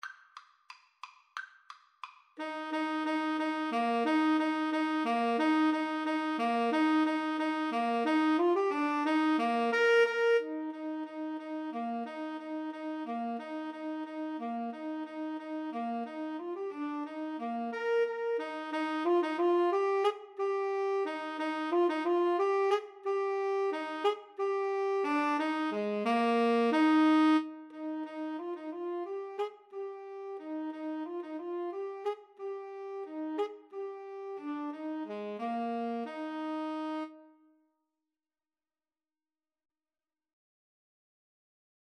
=180 Vivace (View more music marked Vivace)
Eb major (Sounding Pitch) (View more Eb major Music for Clarinet-Tenor Saxophone )
4/4 (View more 4/4 Music)
Classical (View more Classical Clarinet-Tenor Saxophone Music)